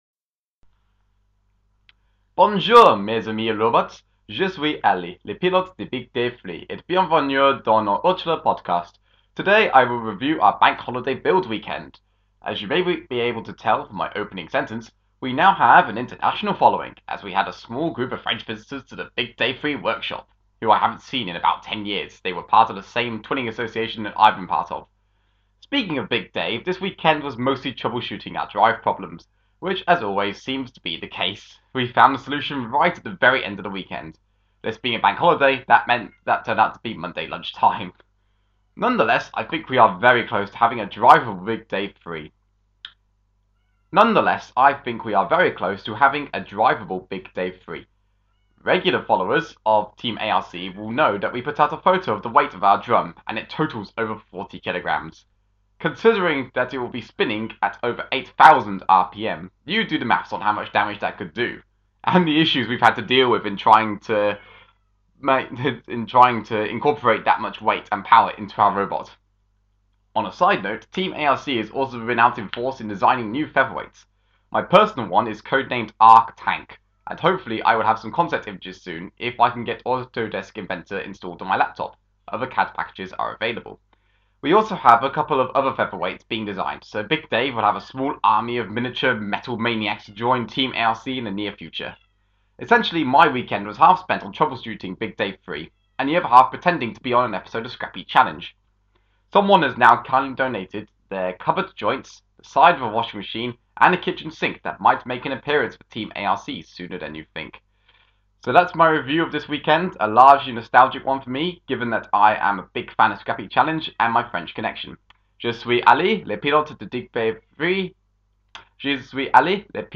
Apologies for the cat in the background.